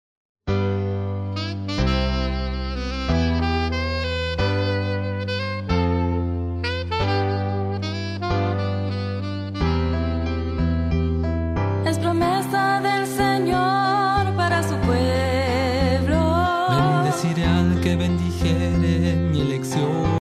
primer CD coral